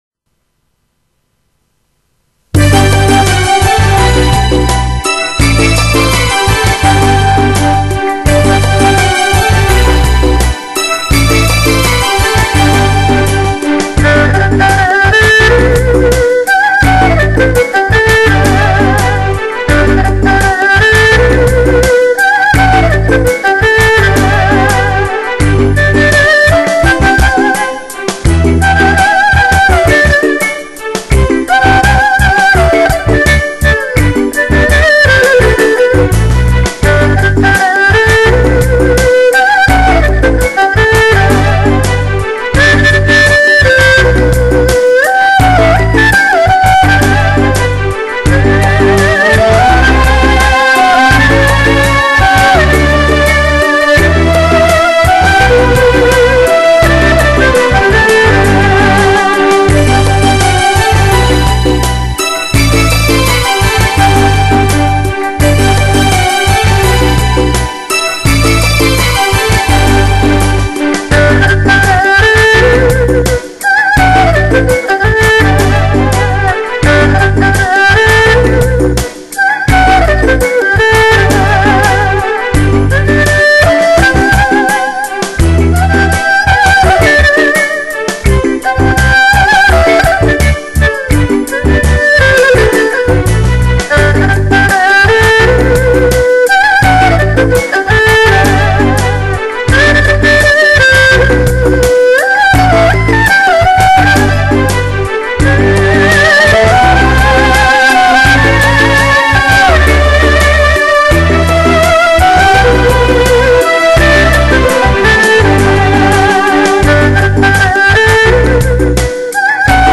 精选十二首极具代表性的经典发烧之作，运用先进的DTS立体声环绕技术，不遗余力的打造中国发烧第一品牌。
二胡演奏